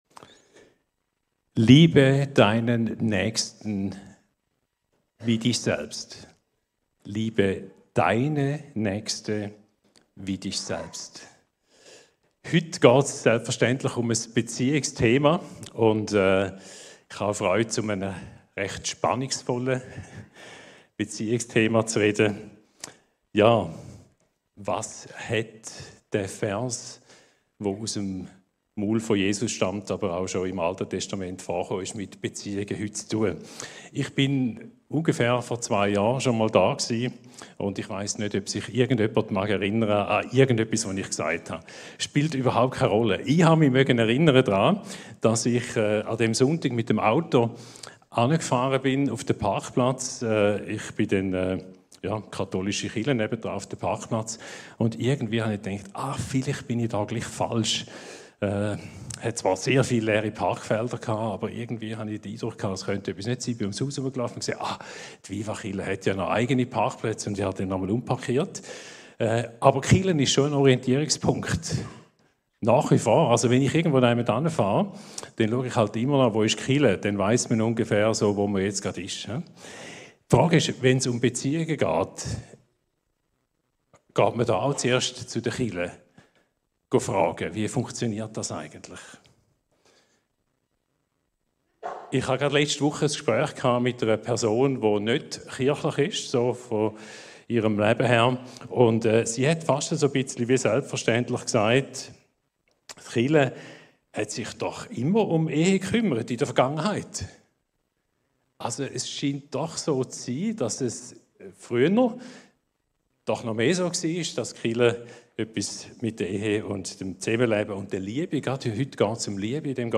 Die neueste Predigt